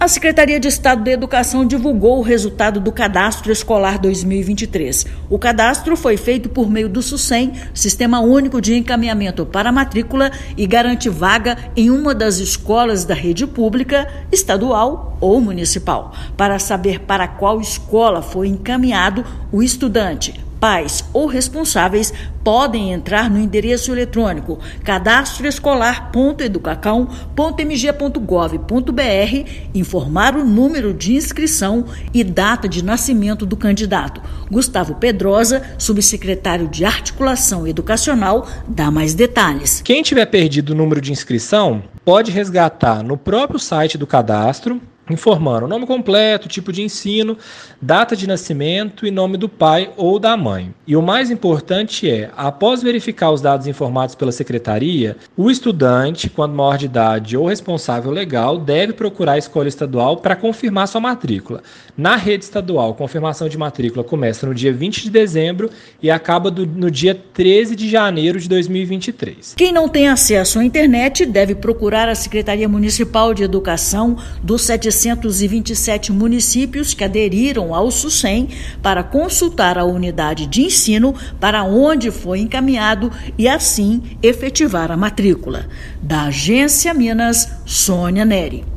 Confirmação da matrícula na rede estadual deve ser feita entre os dias 20/12 e 13/1/2023. Ouça matéria de rádio.